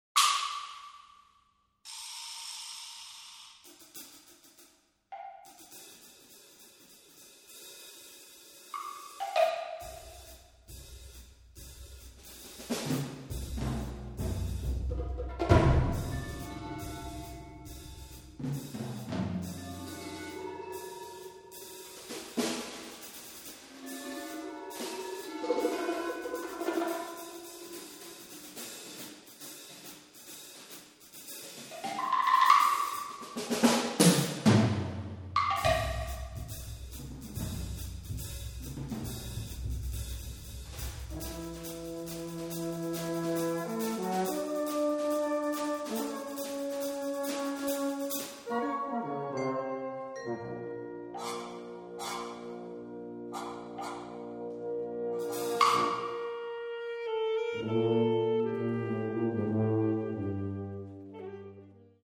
• Bladmuziek voor Harmonie, Fanfare en Brassband